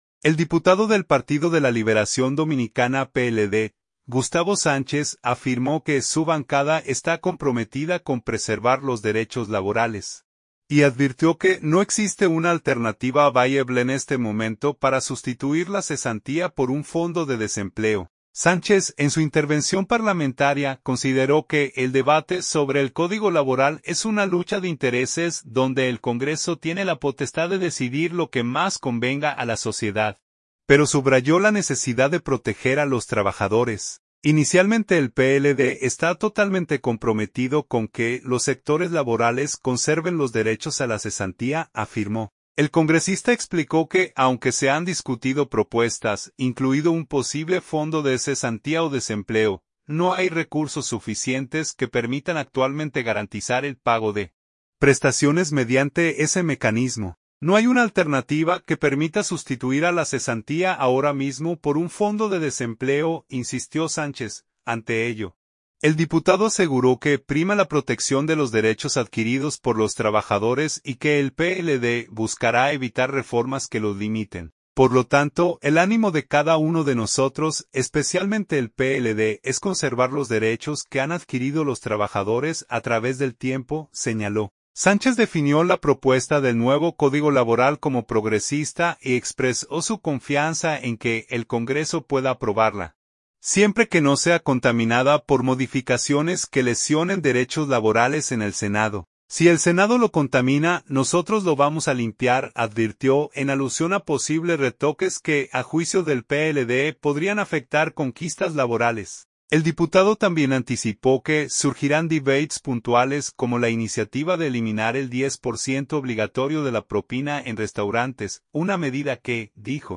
Sánchez, en su intervención parlamentaria, consideró que el debate sobre el Código Laboral es una “lucha de intereses” donde el Congreso tiene la potestad de decidir lo que más convenga a la sociedad, pero subrayó la necesidad de proteger a los trabajadores.